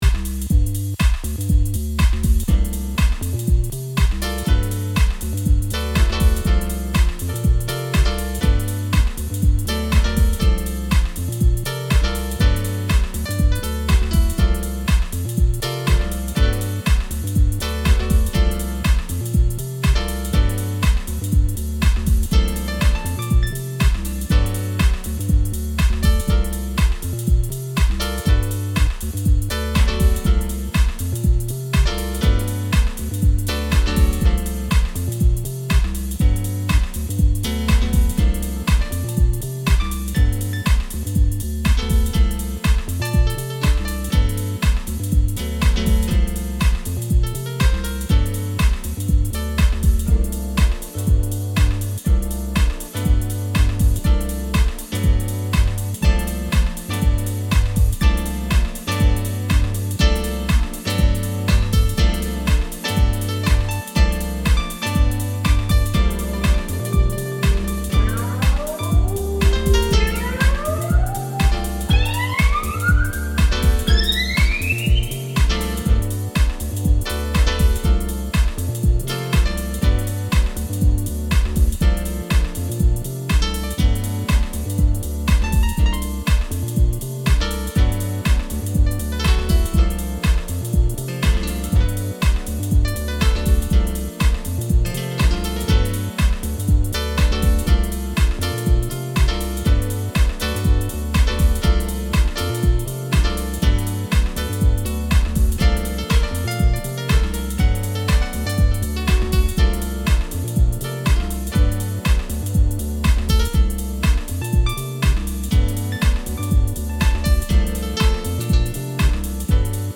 instrumental takes
they still sound as fresh as they did in the early nineties.